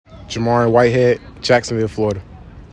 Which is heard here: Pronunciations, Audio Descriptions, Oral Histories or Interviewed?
Pronunciations